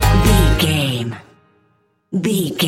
Aeolian/Minor
A♭
acoustic guitar